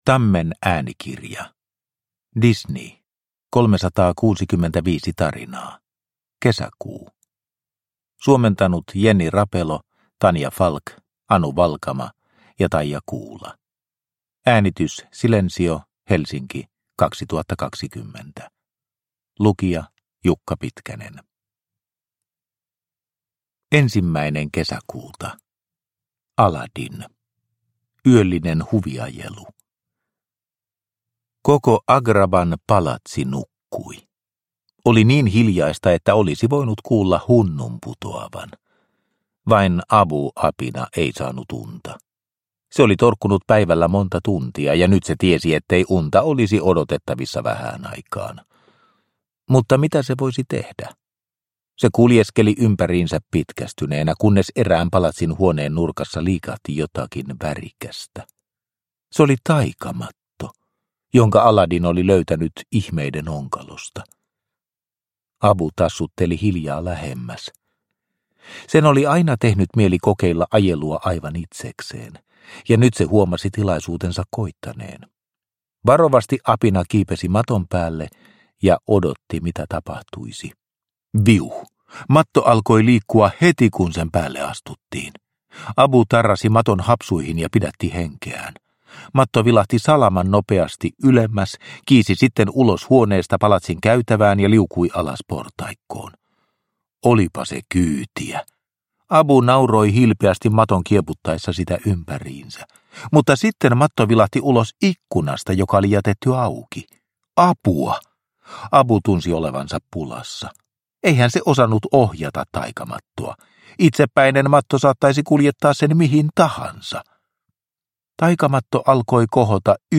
Disney 365 tarinaa, Kesäkuu – Ljudbok – Laddas ner